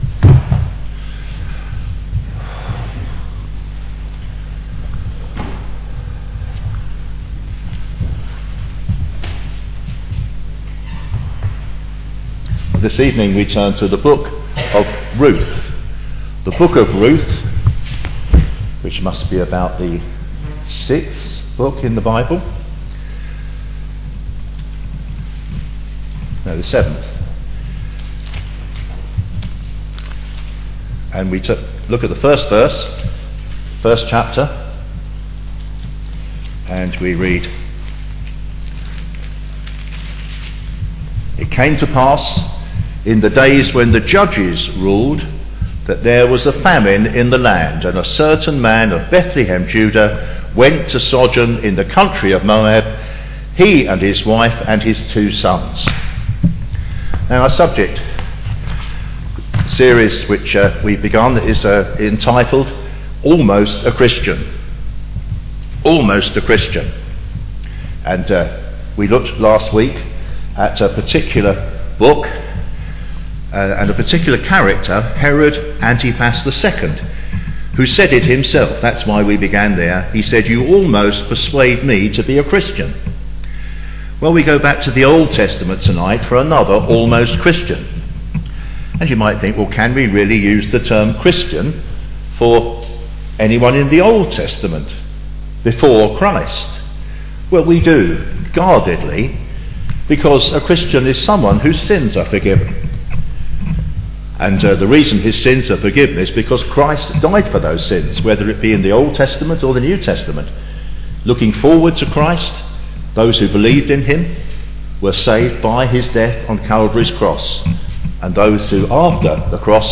Click on the button below to listen to our Recent Sermons.